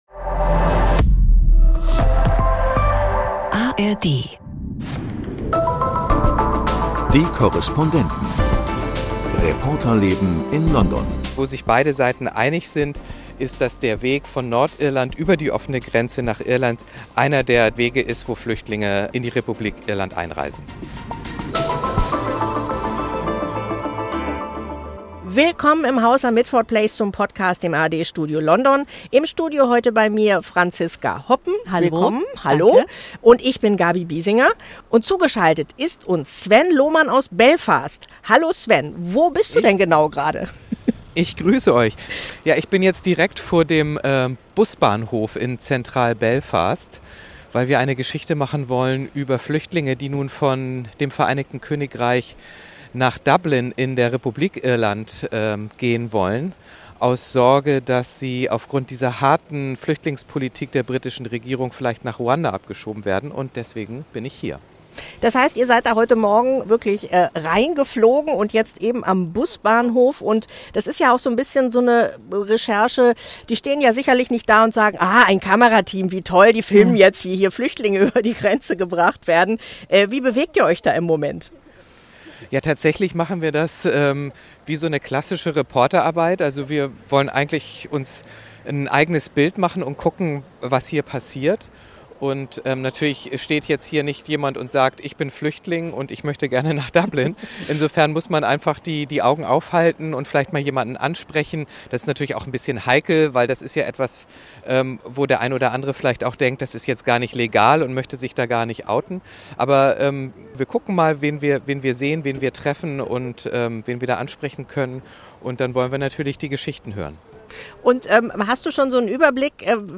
vom Busbahnhof in Belfast